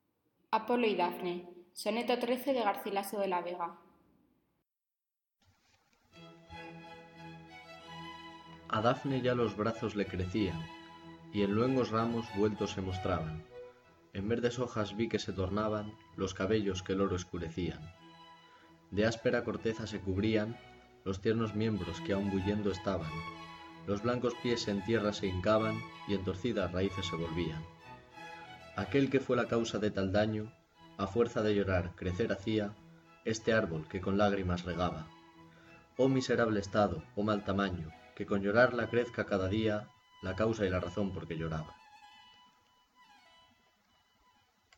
가르실라소의 ''아폴론과 다프네'' 각색 강연